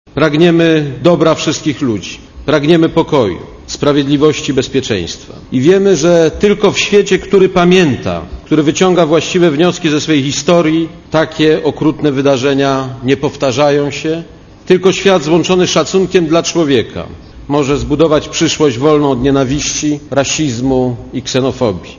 Aleksander Kwaśniewski podczas międzynarodowego forum powiedział - Wyrażamy pragnienie całej ziemskiej społeczności ,aby już nigdy na świecie nie triumfowało zło.
Komentarz audio
kwasniewski-forum.mp3